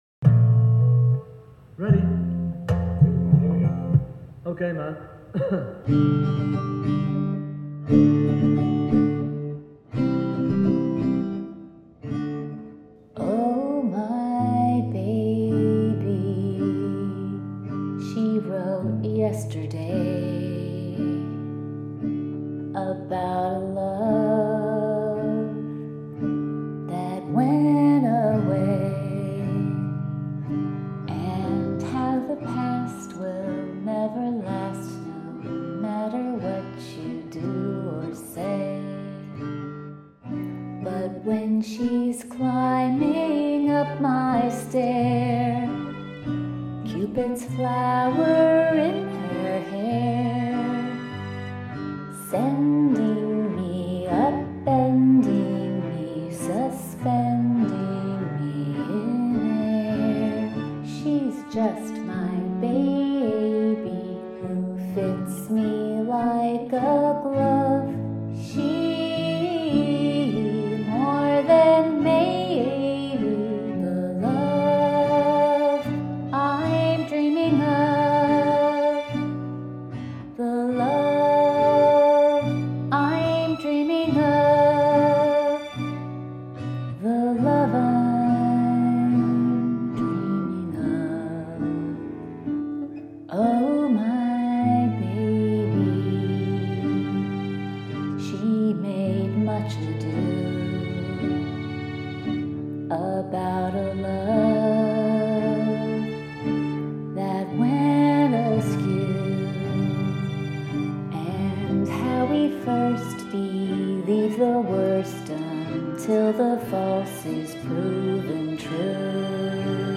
But we did become rather close during the composition and recording of this song.
Chamber pop
G major and D major
• “At one point, if you listen very closely, you can hear the guitar gently weeping.”